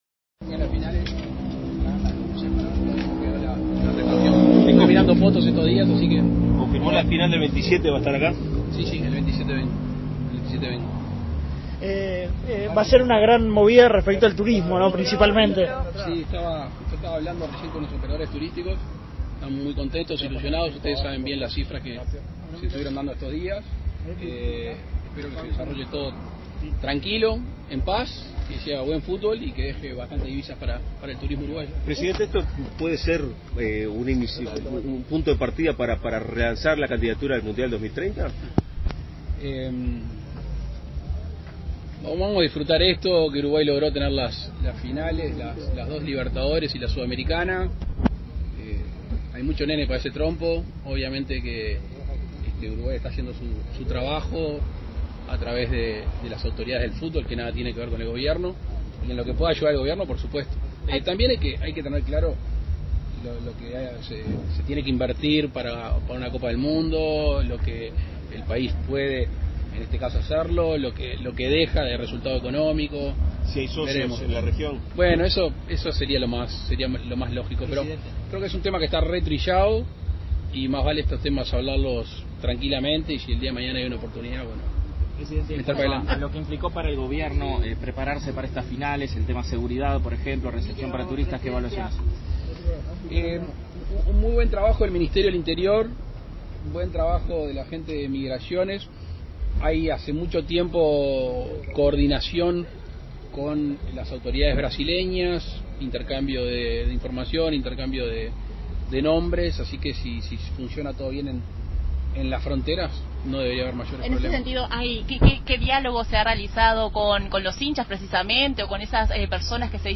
Declaraciones del presidente de la República, Luis Lacalle Pou, a la prensa
Declaraciones del presidente de la República, Luis Lacalle Pou, a la prensa 19/11/2021 Compartir Facebook X Copiar enlace WhatsApp LinkedIn Tras participar en el acto de inauguración del sistema de luces en el estadio Centenario, este 18 de noviembre, el presidente Lacalle Pou efectuó declaraciones a la prensa.